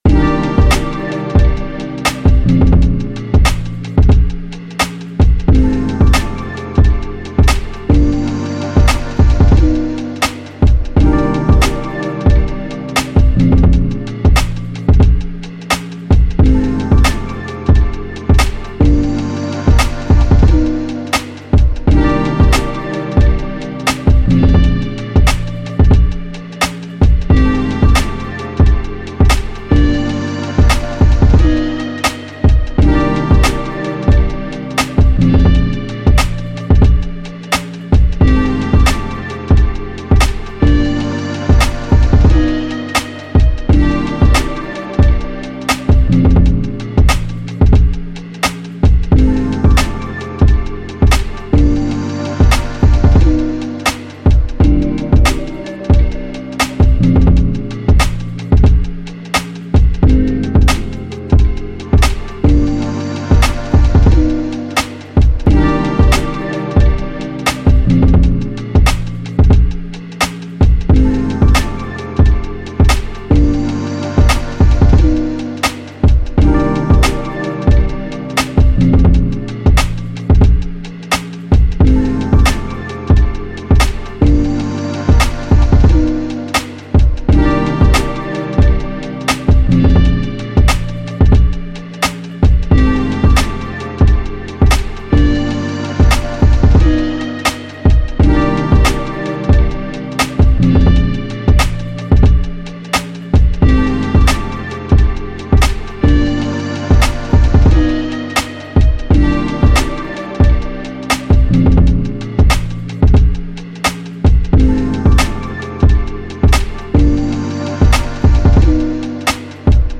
Hip Hop
F major